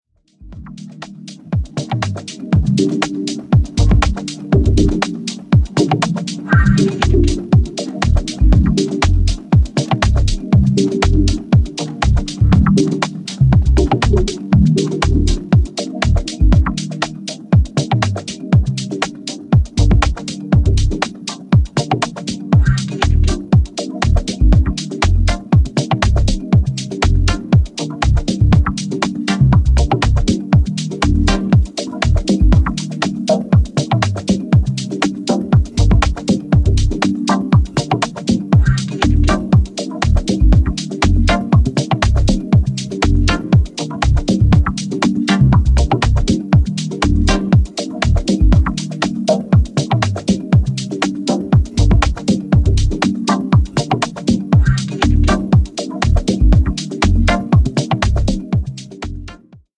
Deep House Dub Techno House